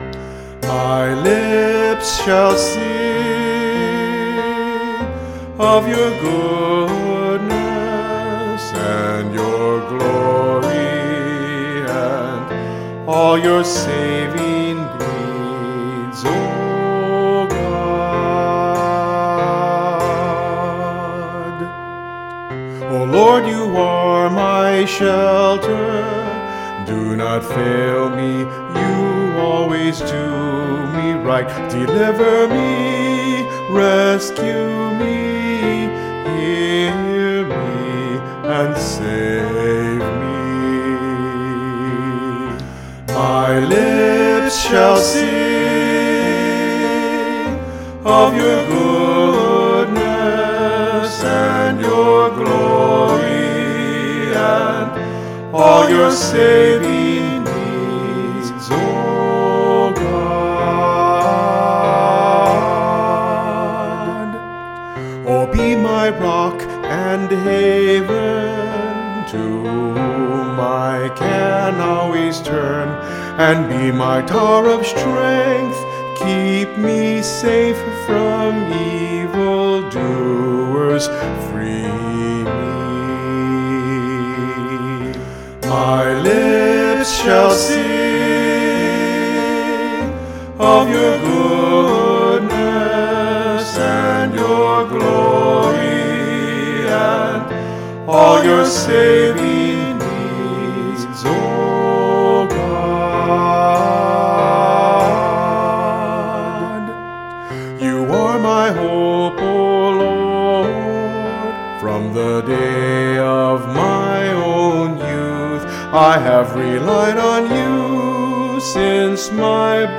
Voice | Downloadable